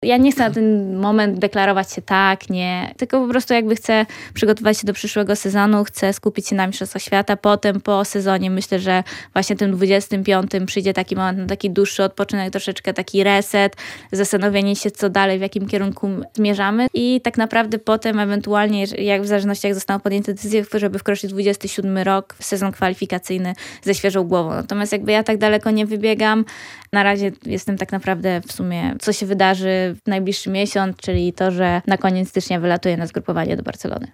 podczas porannej rozmowy w Radiu Lublin